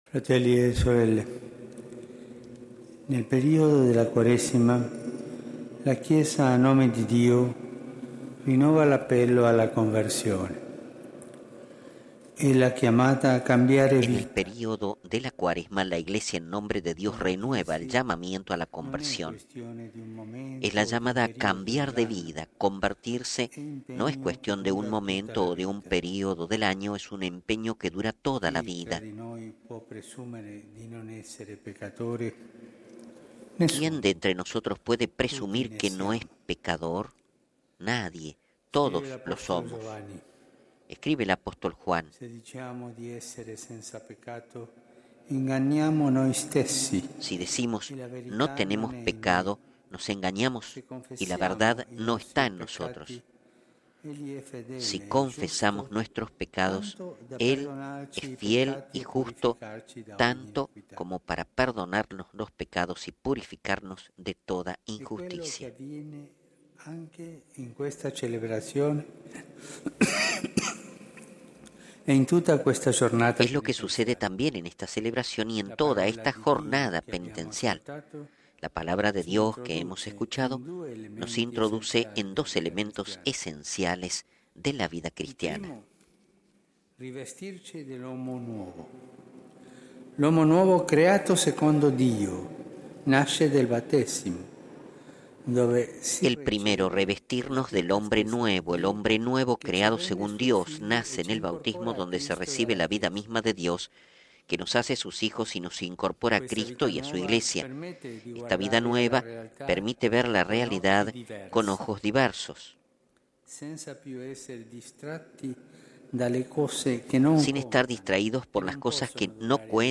(Audio de la crónica radial) RealAudio